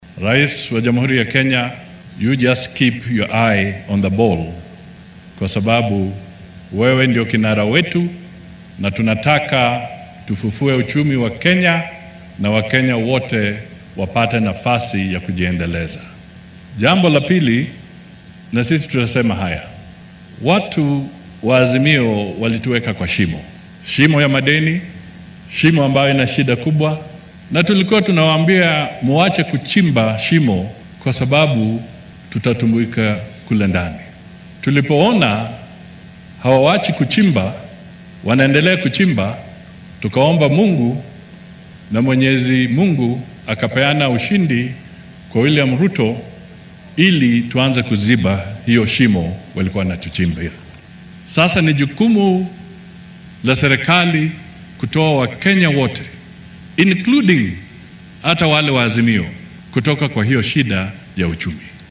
Mudavadi ayaa xilli uu shalay ku sugnaa fagaaraha Ole Ntimama Stadium ee ismaamulka Narok dhaliil u jeediyay mucaaradka. Waxaa uu hoosta ka xarriiqay in maamulkii hore uu sabab u ahaa hoos u dhaca dhaqaale ee wadanka ka jiro.